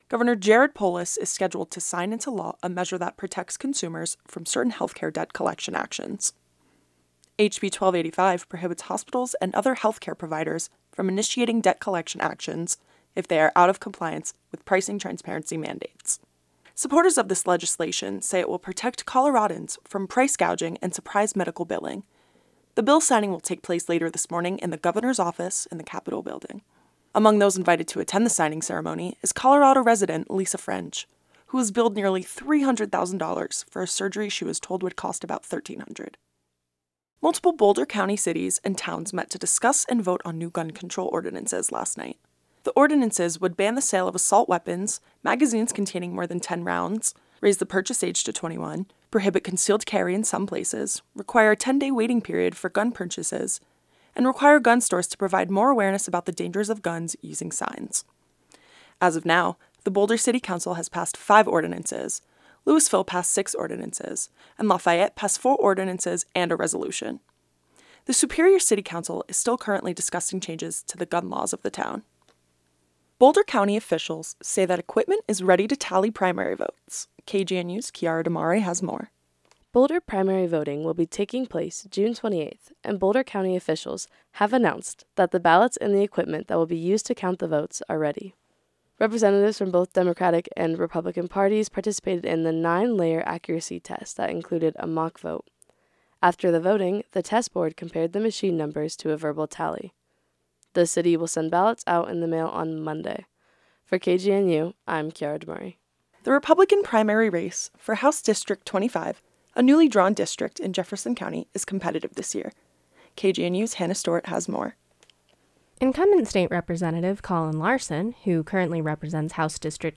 Headlines — June 8, 2022